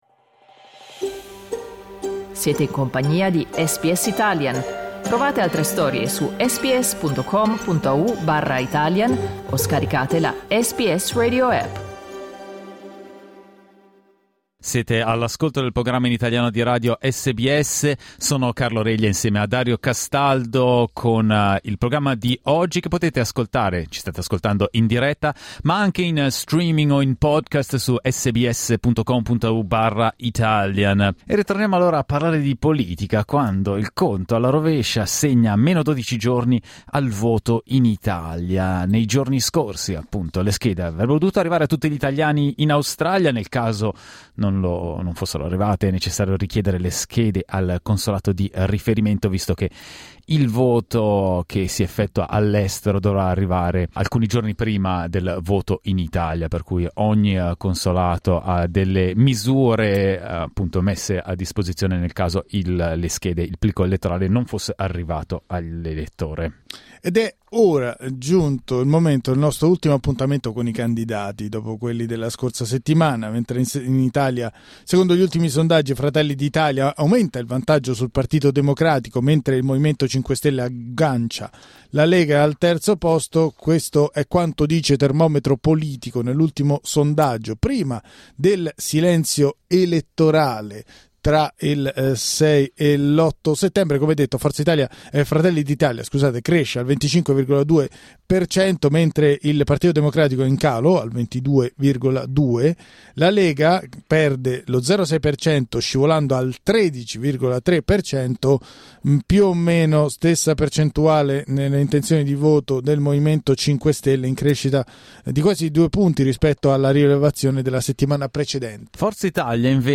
A 12 giorni dal voto in Italia, gli ospiti di SBS Italian per il quarto dibattito elettorale sono stati i candidati